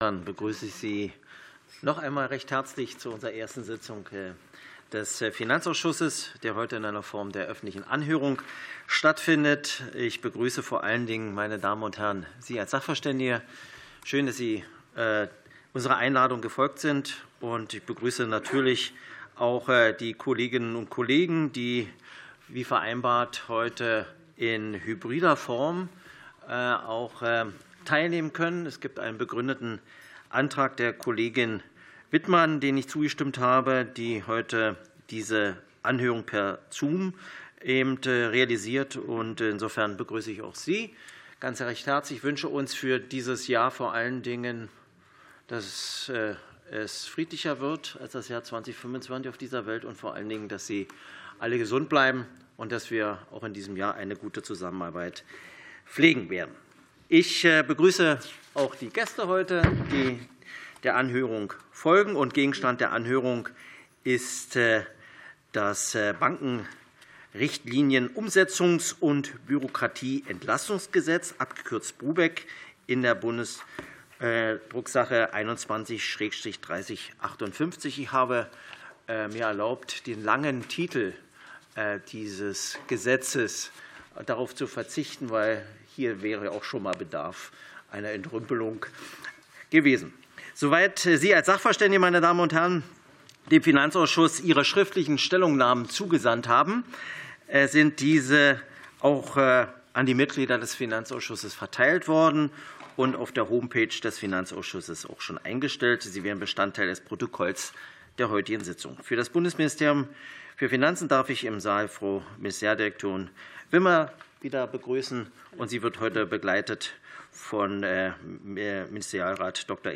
Anhörung des Finanzausschusses